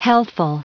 Prononciation du mot healthful en anglais (fichier audio)
Prononciation du mot : healthful